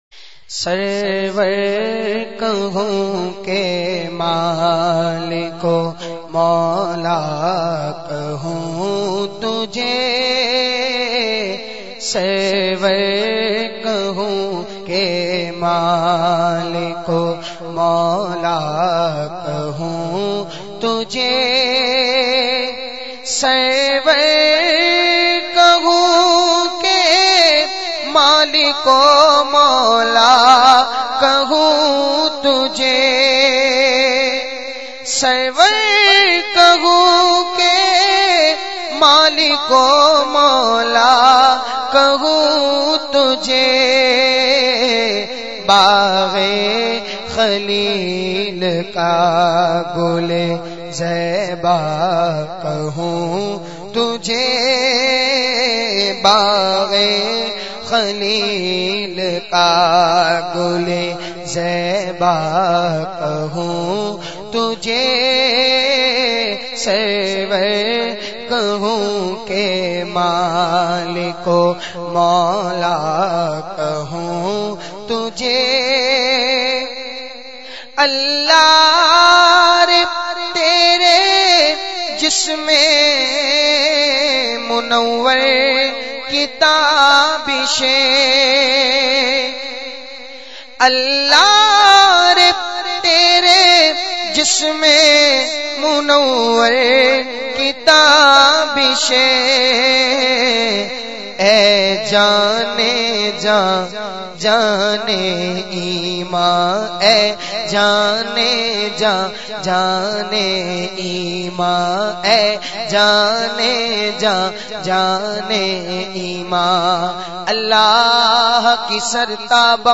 Naat Sharif Sarwar kahoon ke Maliko Maula
نعت